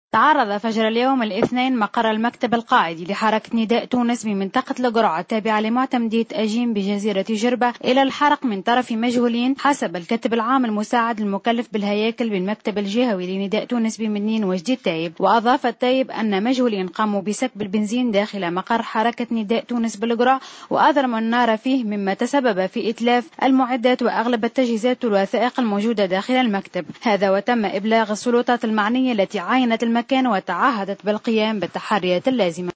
أكثر تفاصيل مع مراسلتنا